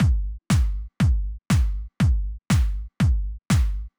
Drumloop 120bpm 03-C.wav